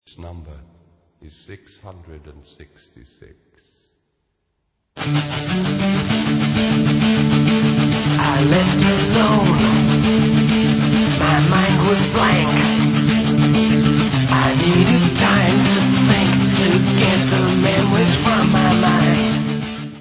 sledovat novinky v oddělení Heavy Metal
Rock